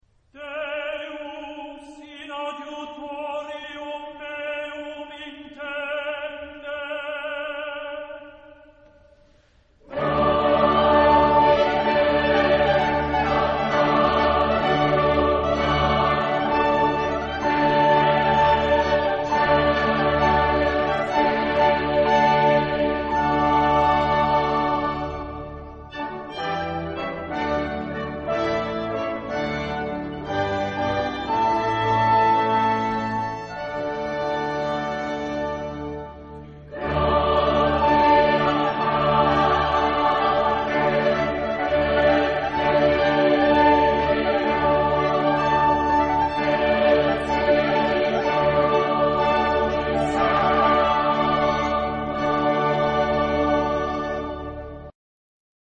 Genre-Style-Forme : Baroque ; Sacré
Type de choeur : SSATBB  (6 voix mixtes )
Instrumentation : Basse continue  (6 partie(s) instrumentale(s))
Tonalité : sol majeur